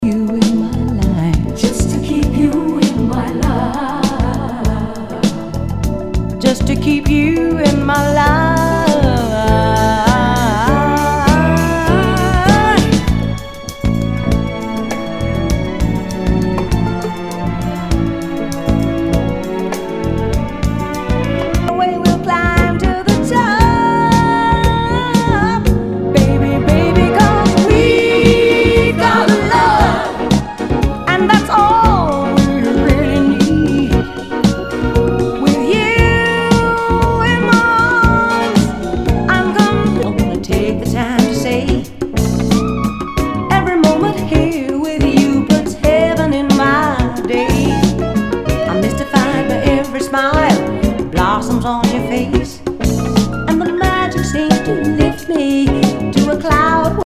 ジャズ・ヴォーカル
SOUL/FUNK/DISCO